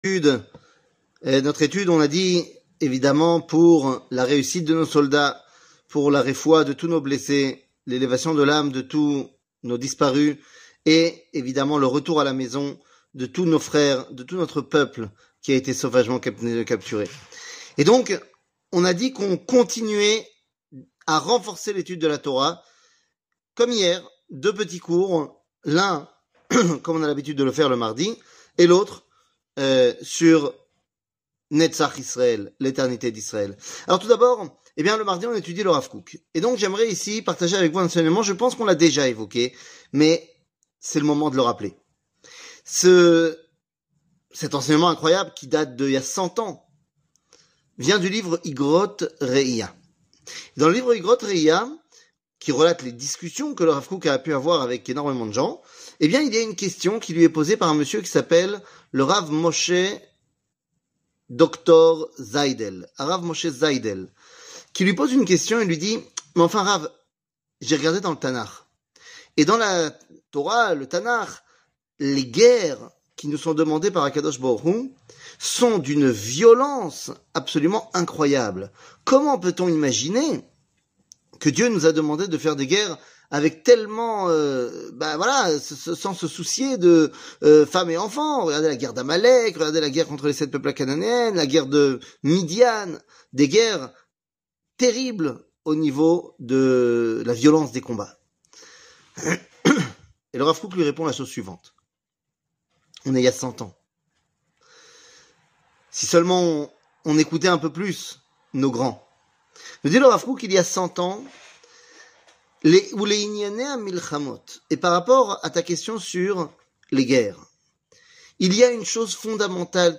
Rav Kook, Igrot Reiya, Detruire le mal 00:05:39 Rav Kook, Igrot Reiya, Detruire le mal שיעור מ 10 אוקטובר 2023 05MIN הורדה בקובץ אודיו MP3 (5.17 Mo) הורדה בקובץ וידאו MP4 (7.99 Mo) TAGS : שיעורים קצרים